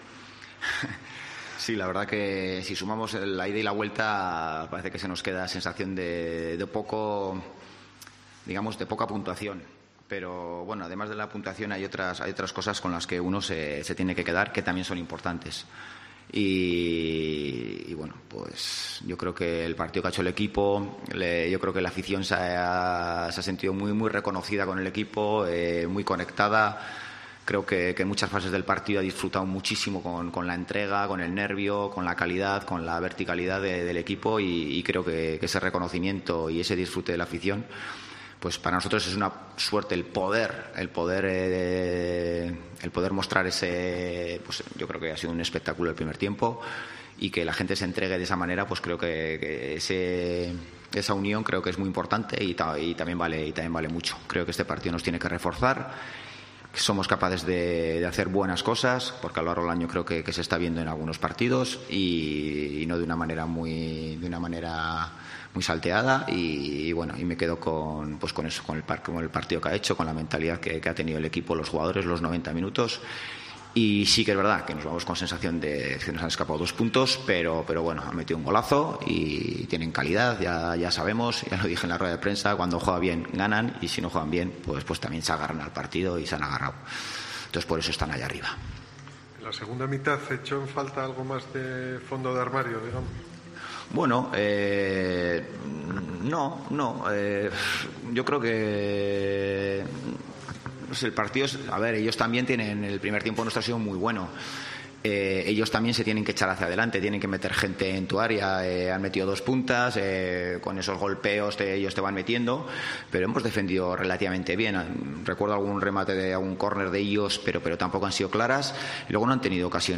Rueda de prensa Ziganda (post Eibar)